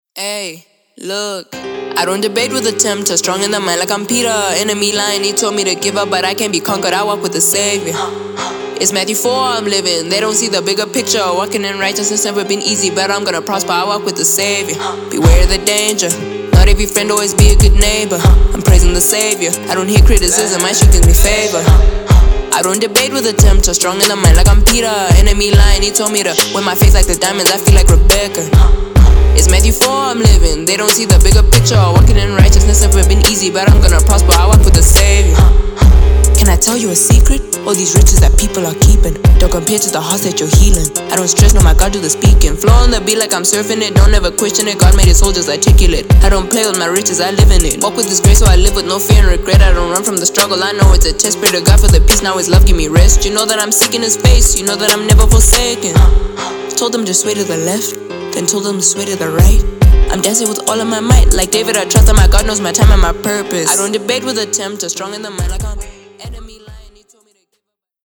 MIXED AND MASTERED
Rap (Recorded on Phone Mic)
AFTER MIXING AND MASTERING